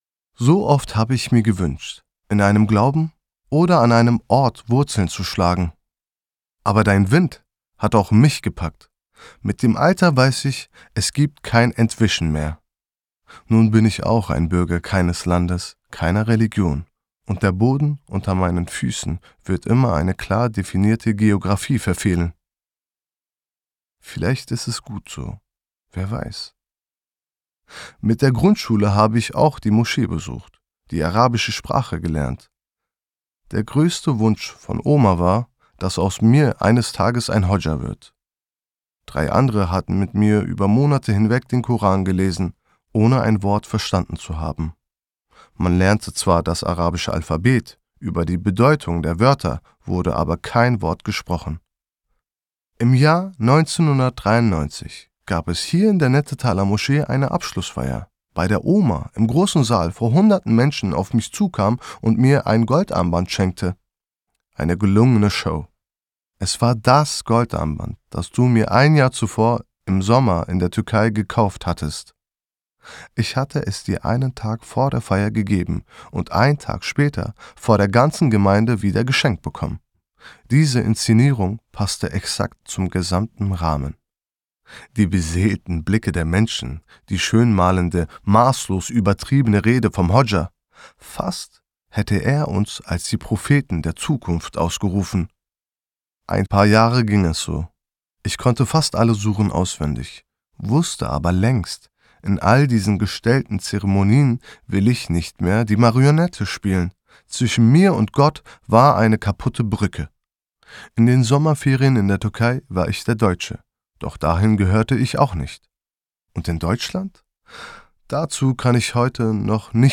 Unser Deutschlandmärchen ist eine Familiengeschichte in vielen Stimmen.